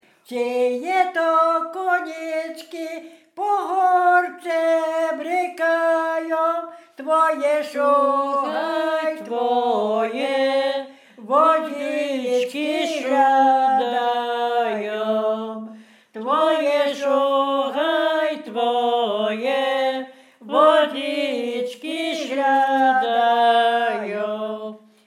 Bukowina Rumuńska
Tajdany
liryczne miłosne weselne wesele przyśpiewki tajdany